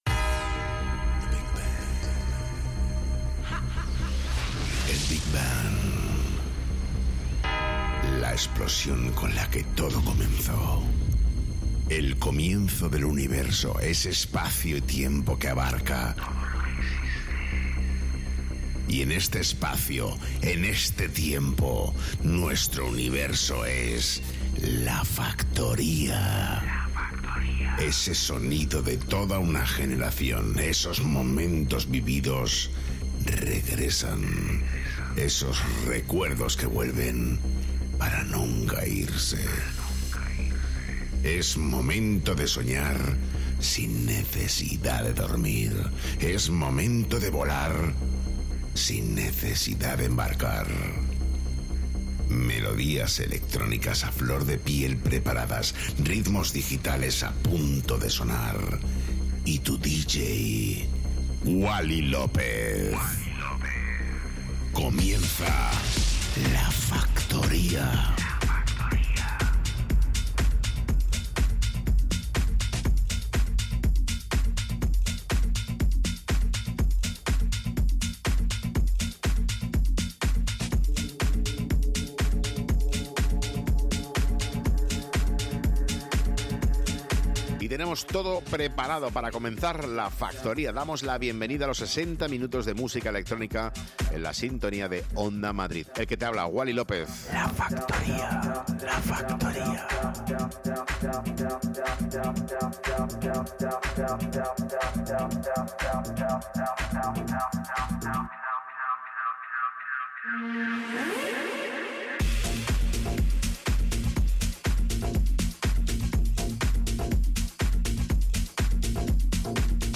el DJ más internacional de Madrid